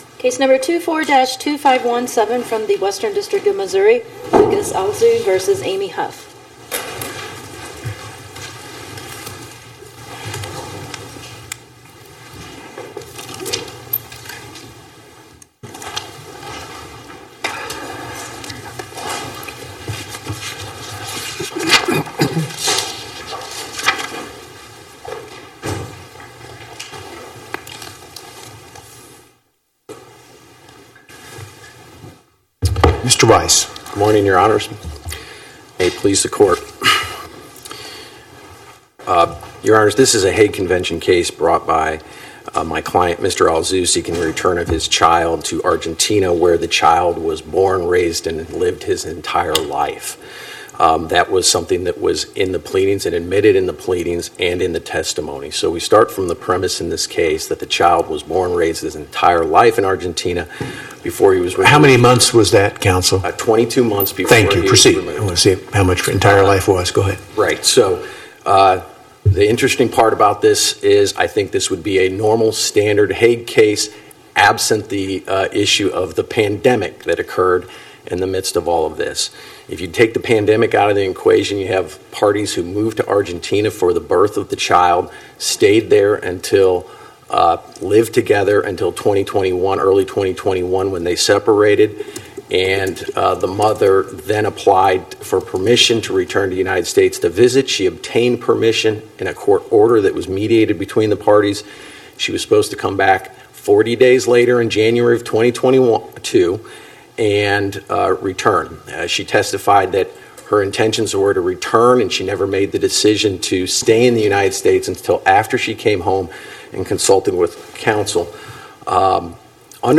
Oral argument argued before the Eighth Circuit U.S. Court of Appeals on or about 11/20/2025